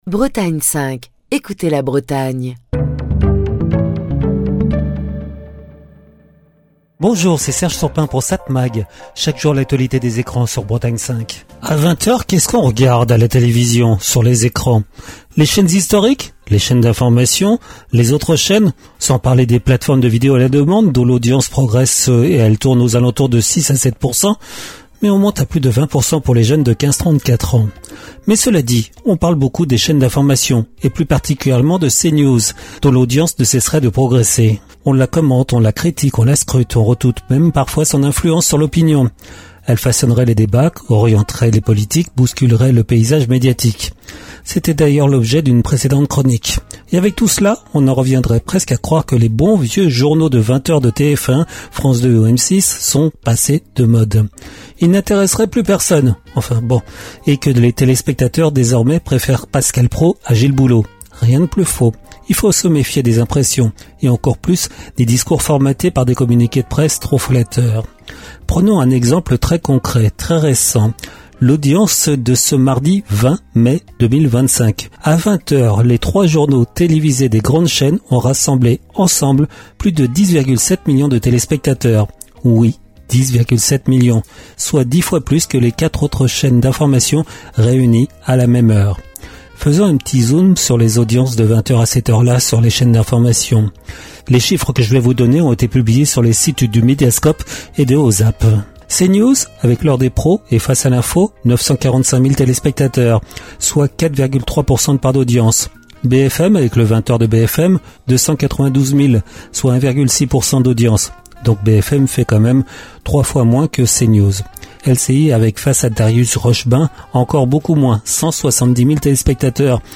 Chronique du 22 mai 2025.